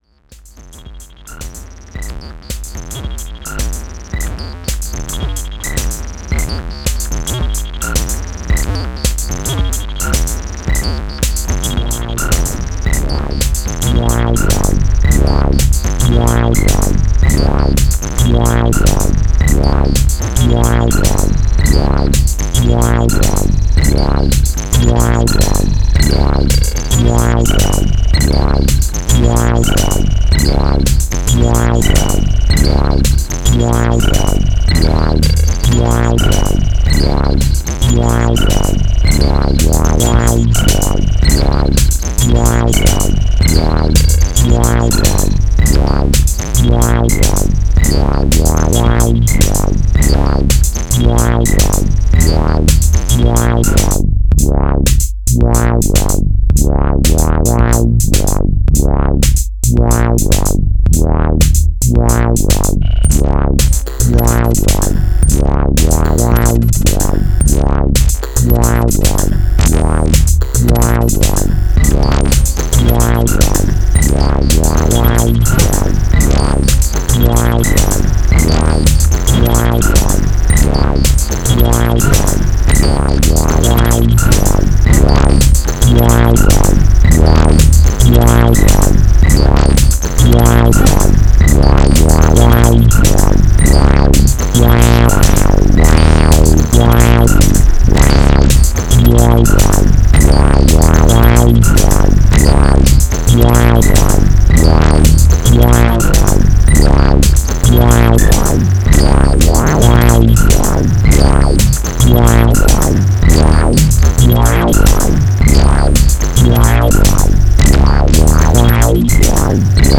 It’s a shame, but it’s like second patch in Grid that I actually recorded, nothing serious, just wavetable FM over factory sample, plus funky beat.